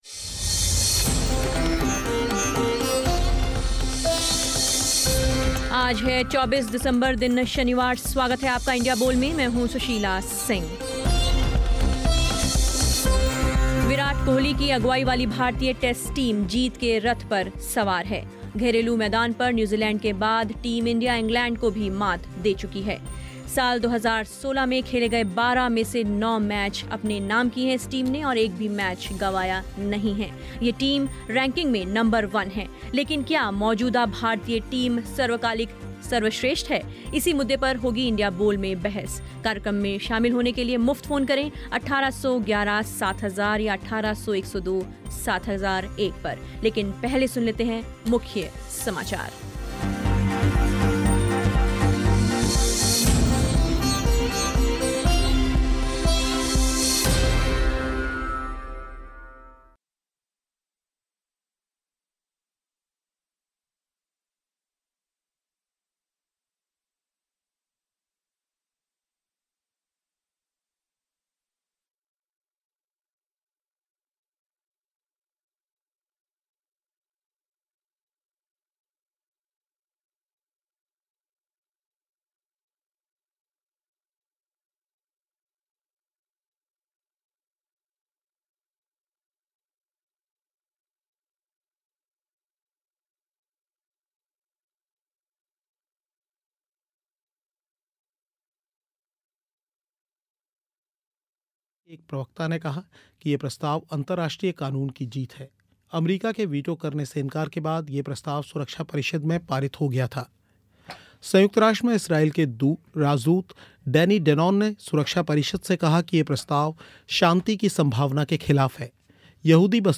क्या मौजूदा भारतीय टीम सर्वकालिक सर्वश्रेष्ठ है?इसी मुद्दे पर हुई इंडिया बोल में बहस.